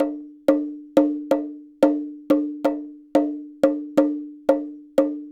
Bongo 14.wav